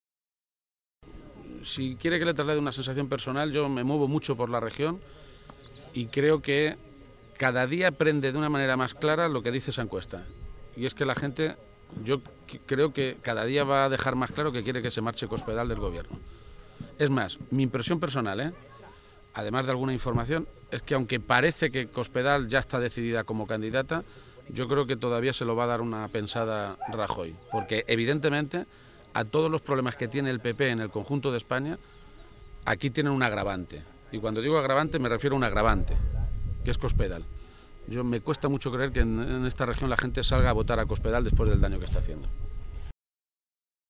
En Toledo, a preguntas de los medios de comunicación, García-Page constataba que cada vez más está claro que hay un sentimiento muy mayoritario en la región.
Cortes de audio de la rueda de prensa